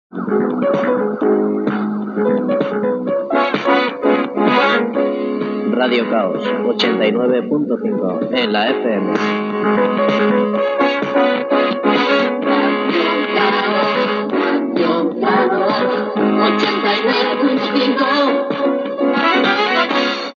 Identificació cantada de l'emissora